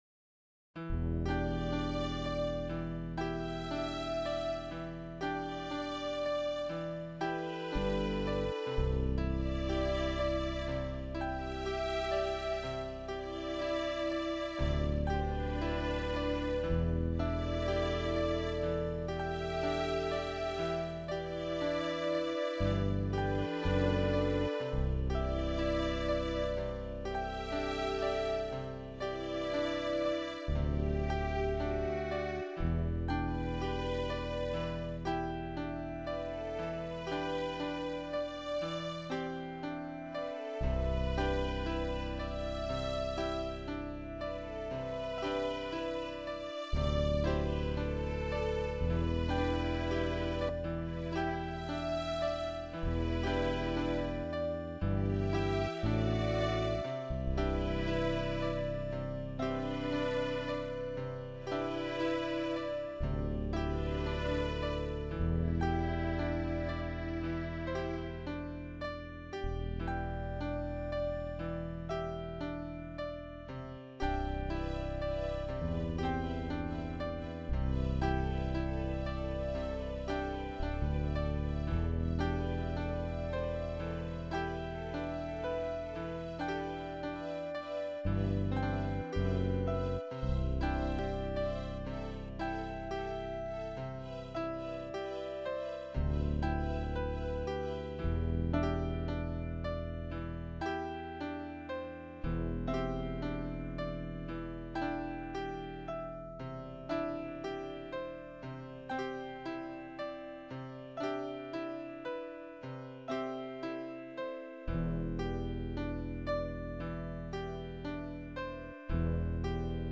Another peaceful piano tune with Strings Esemble